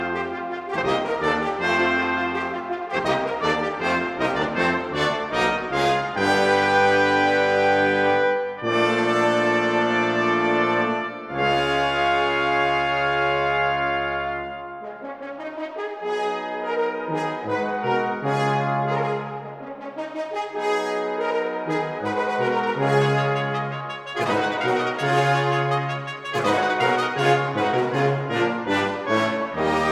Жанр: Эстрада
# Orchestral